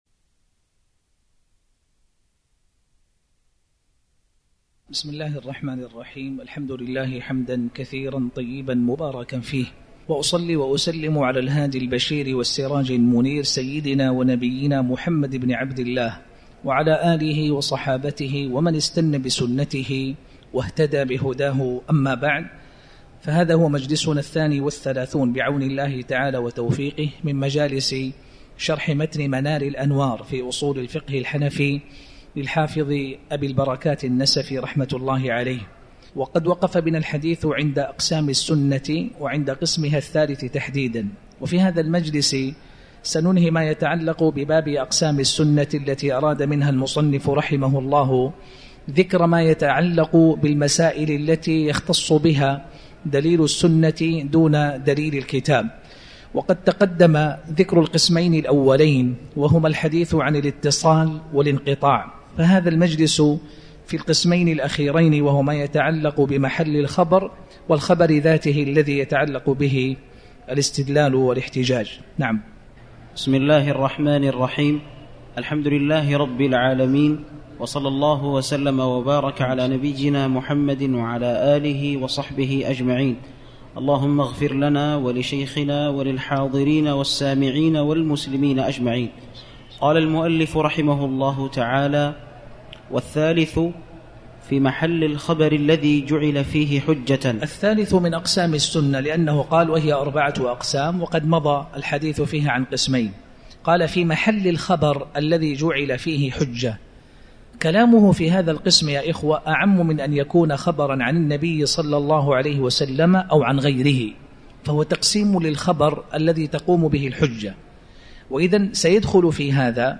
تاريخ النشر ٨ صفر ١٤٤٠ هـ المكان: المسجد الحرام الشيخ